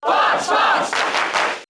Fox's cheer in the US and PAL versions of Brawl.
Fox_Cheer_English_SSBB.ogg